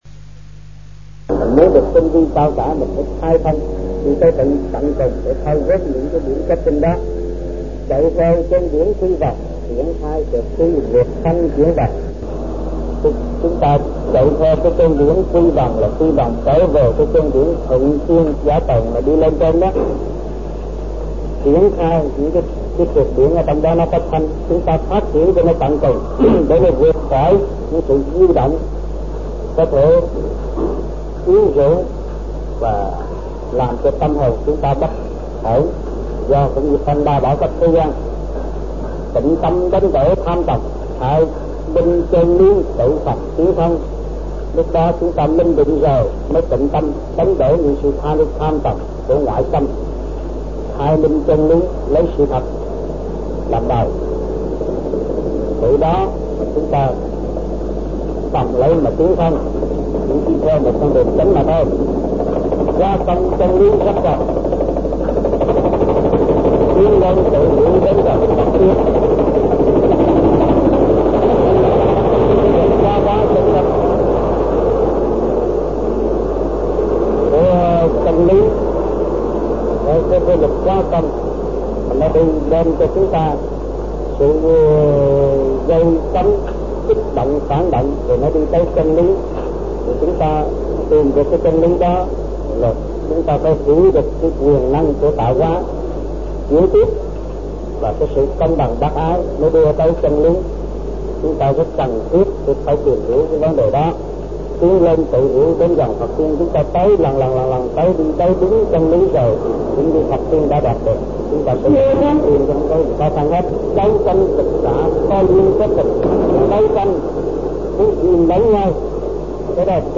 Việt Nam Trong dịp : Sinh hoạt thiền đường >> wide display >> Downloads